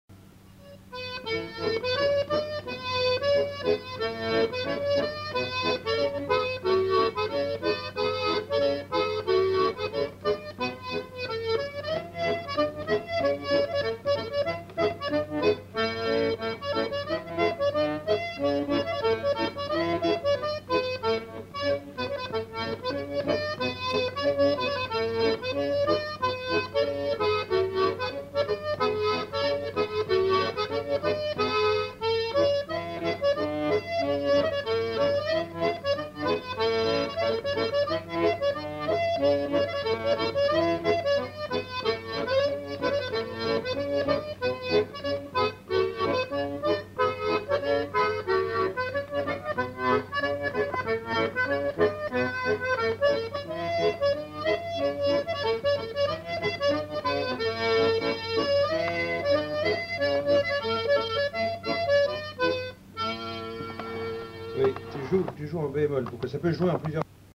Instrumental. Accordéon diatonique. Bretagne
Aire culturelle : Bretagne
Genre : morceau instrumental
Instrument de musique : accordéon diatonique